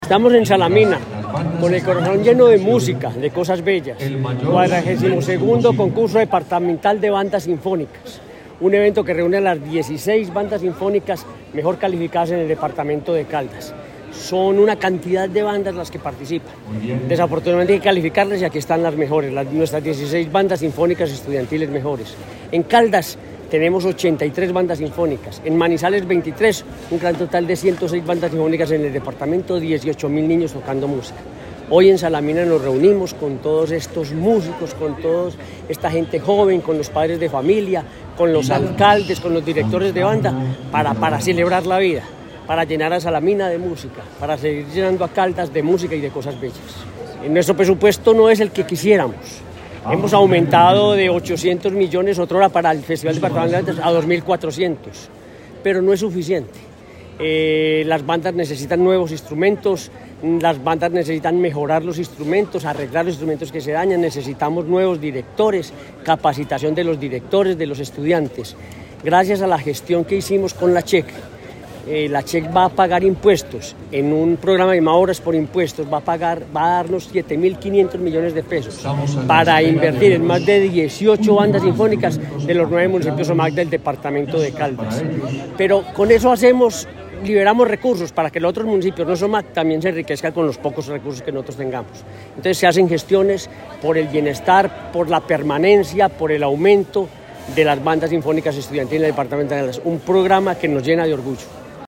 Gobernador, Henry Gutiérrez Ángel.